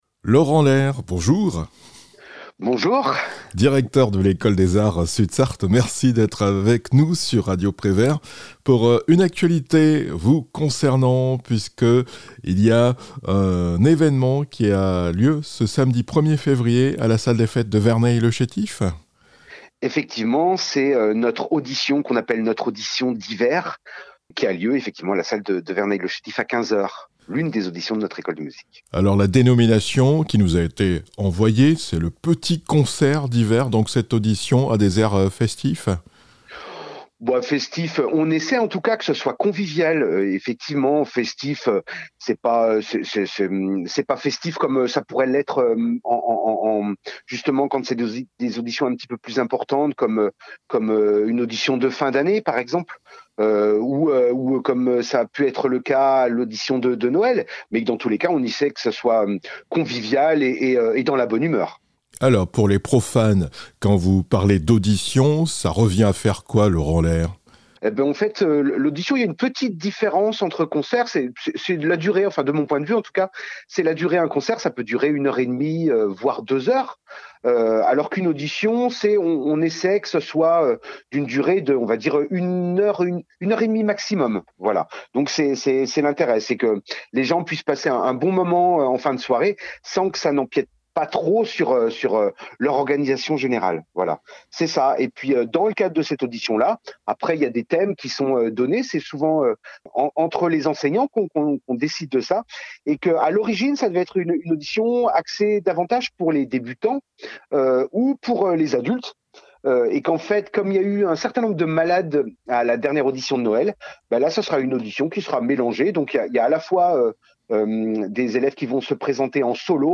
Sud Sarthe : petit concert d'hiver avec l'Ecole des arts